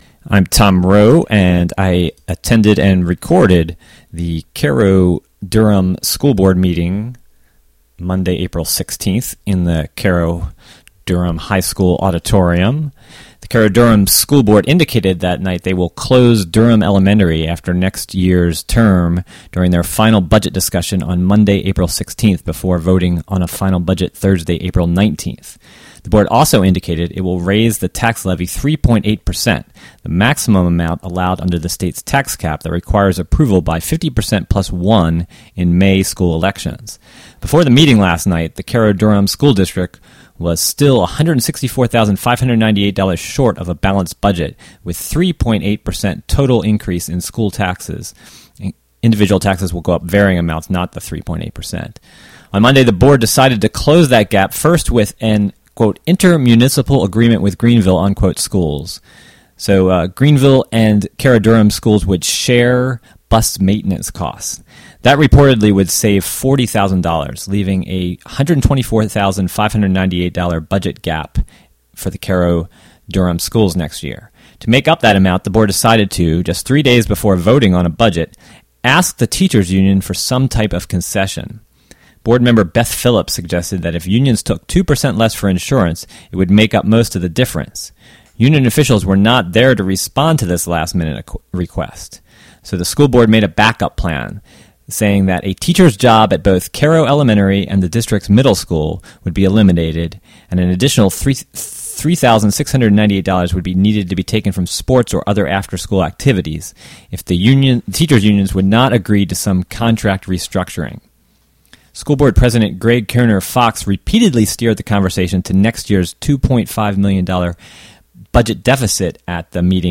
Cairo-Durham BOE Special Budget Meeting: Apr 17, 2012: 2pm - 2:30 pm